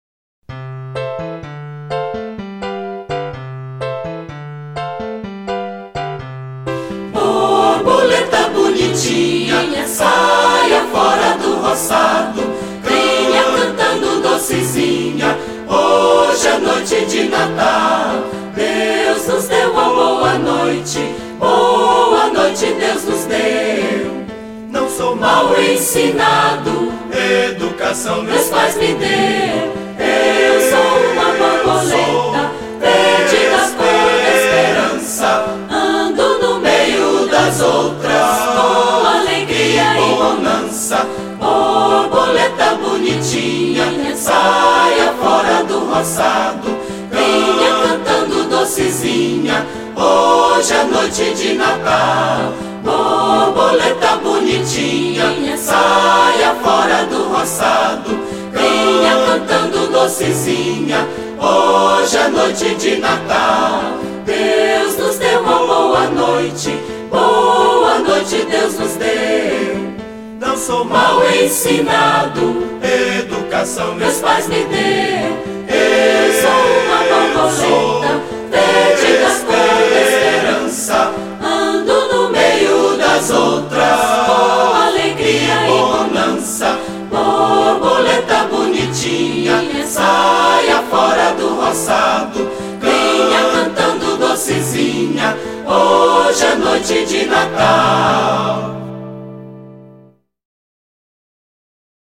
881   03:10:00   Faixa:     Folclore Piauiense